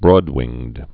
(brôdwĭngd)